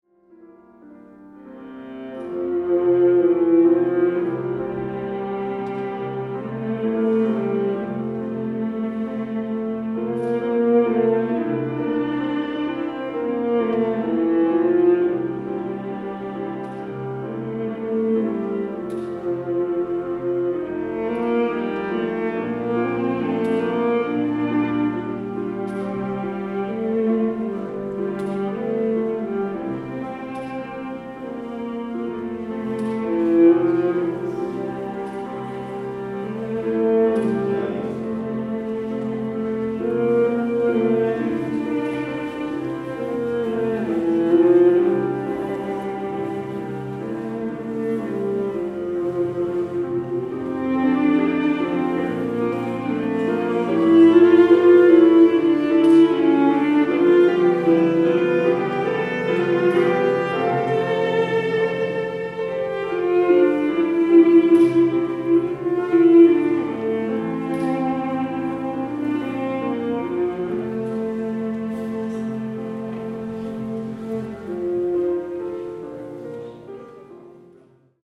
Live